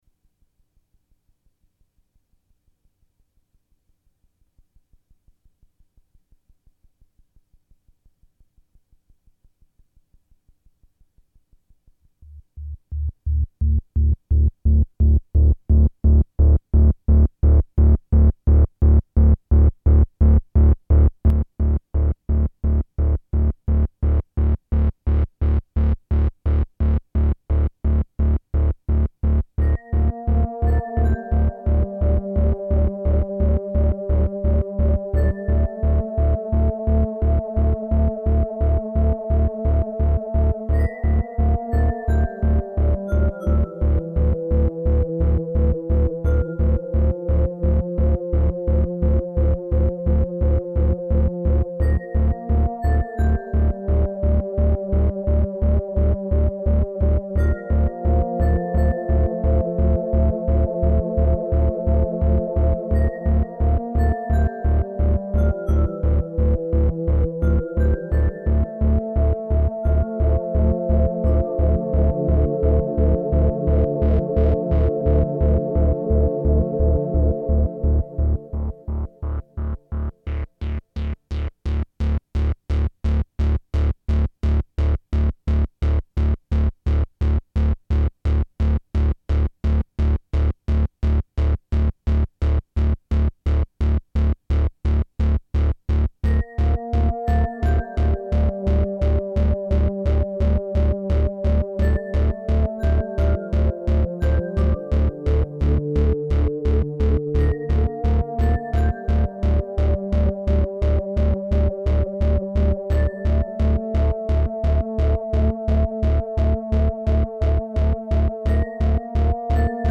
These were improvisations all recorded in late 2005.
I was experimenting with converting the drone to a pulse.